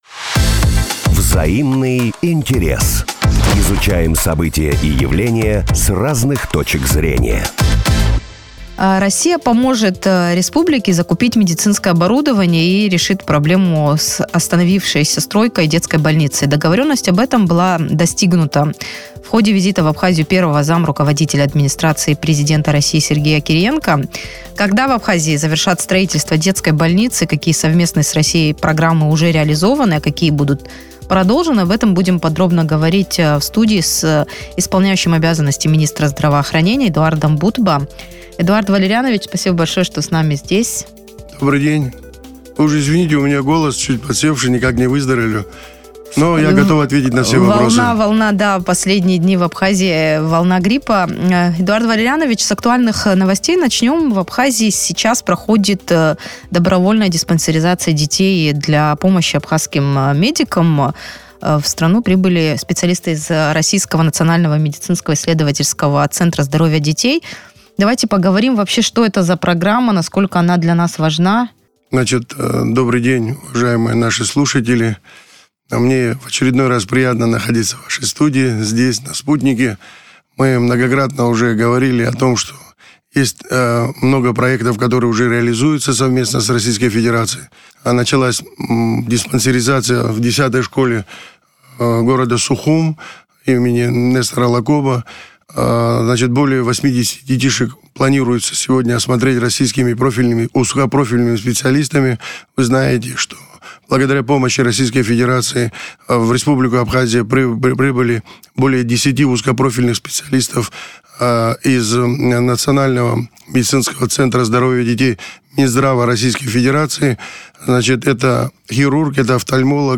О том, какие проекты реализуются в рамках сотрудничества Абхазии и России, как взаимодействуют врачи двух стран, об этом в эфире радио Sputnik рассказал министр здравоохранения Эдуард Бутба.